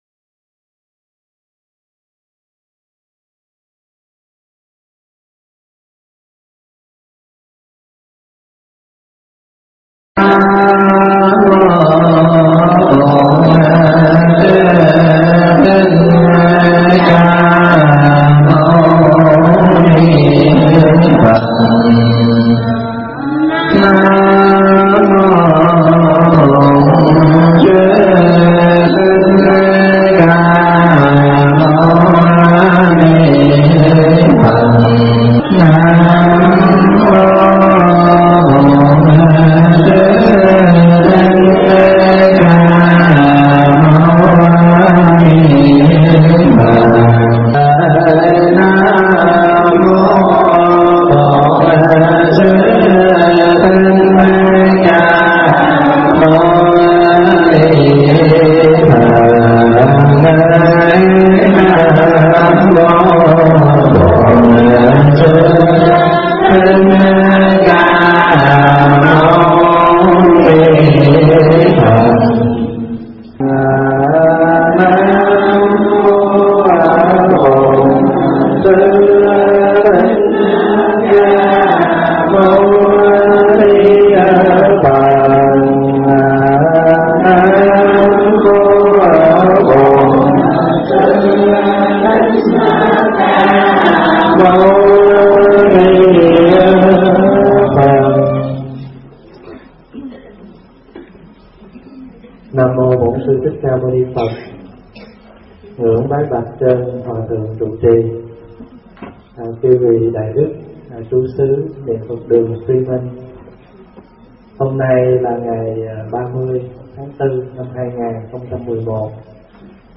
Khóa Tu Một Ngày